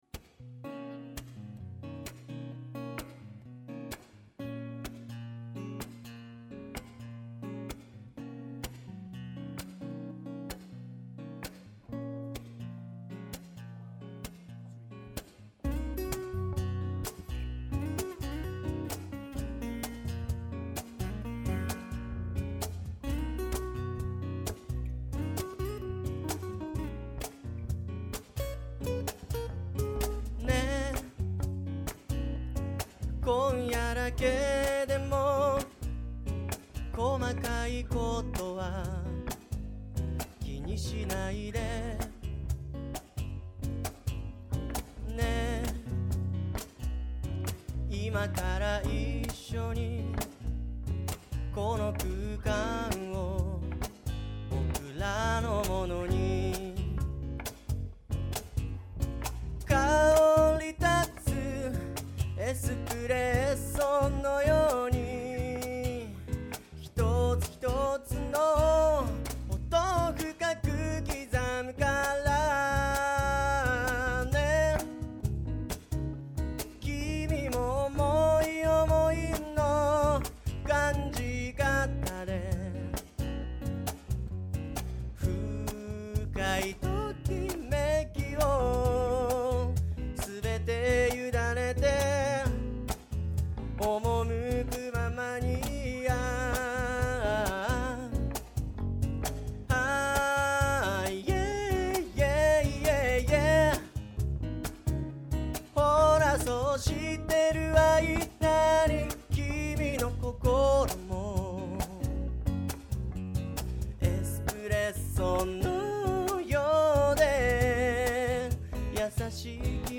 米子を中心にリラックスできるおシャレな音楽を目指して活動中。